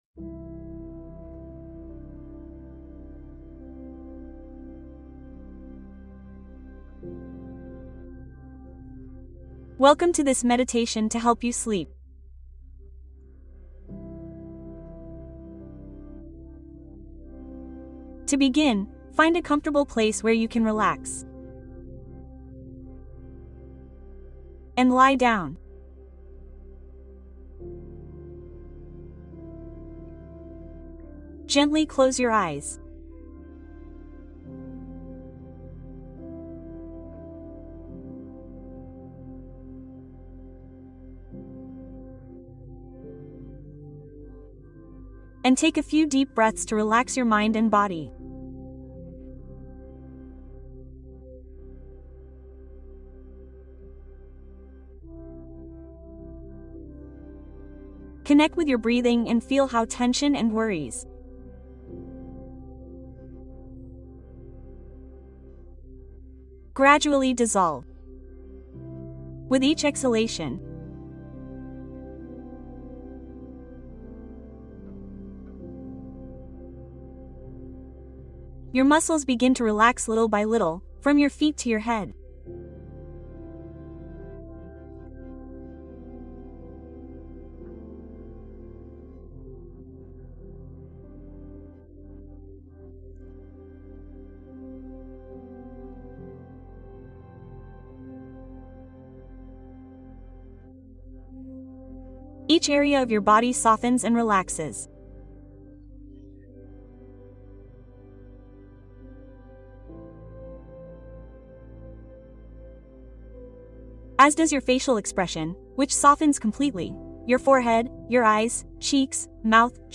Duerme profundo ✨ Meditación guiada para liberarte del insomnio